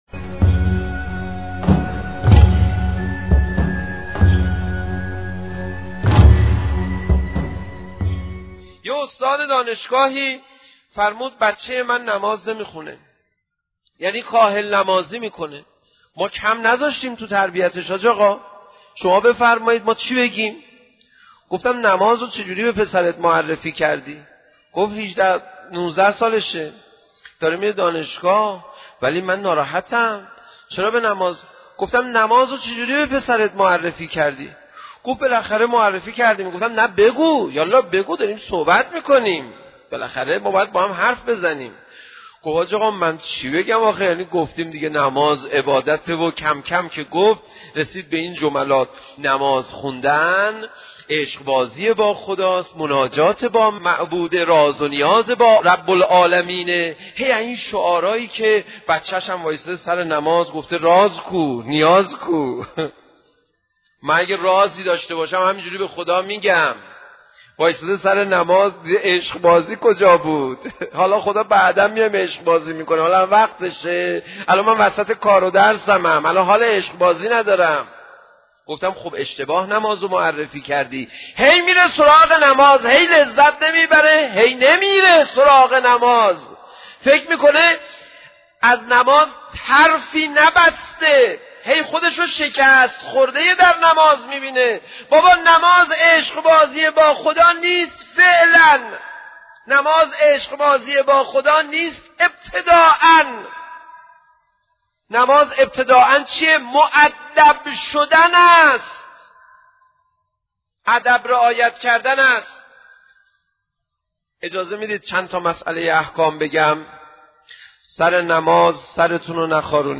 سخنرانی حجت الاسلام پناهیان در مورد نماز